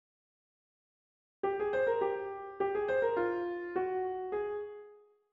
grumpy (clarinet):